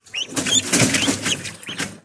На этой странице собраны разнообразные звуки цыплят: от милого писка до забавного квохтания.
Звук пищания цыпленка за решеткой